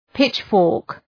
Προφορά
{‘pıtʃ,fɔ:rk}